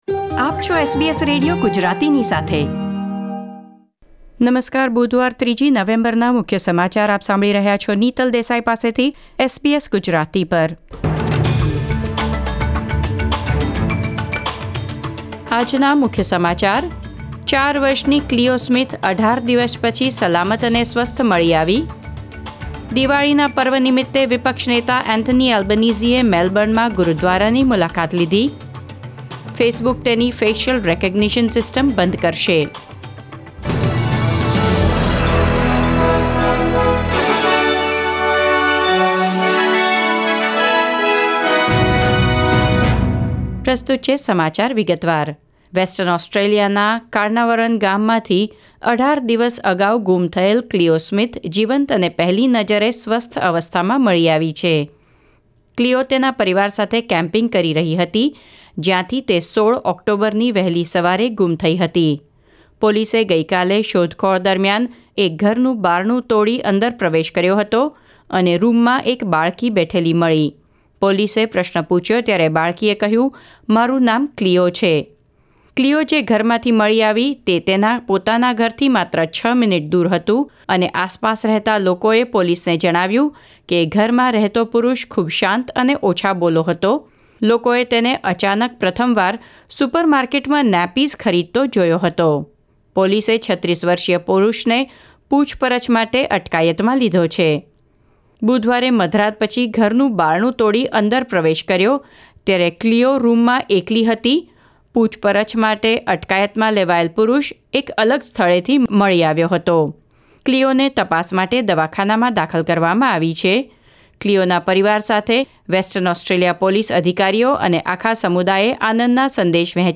SBS Gujarati News Bulletin 3 November 2021